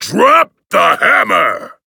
Vo_stump_smash_catchphrase_01.ogg